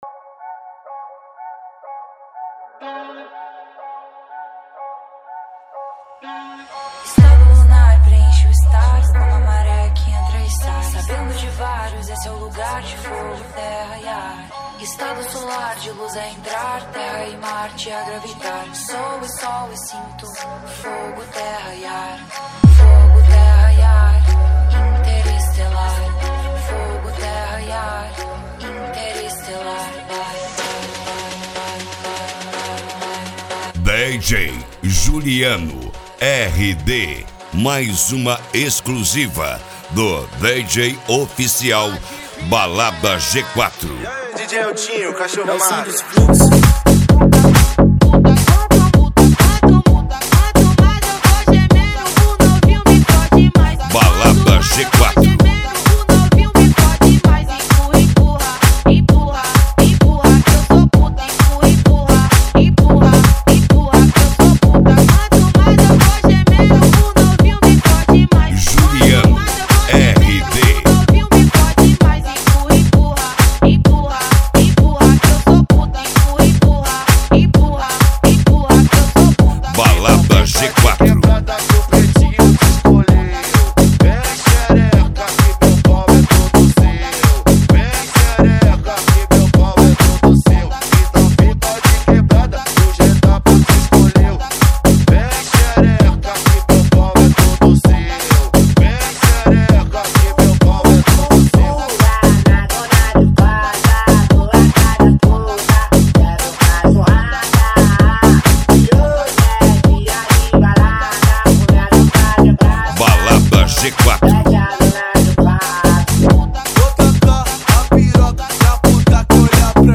Funk ,Dance , eletro house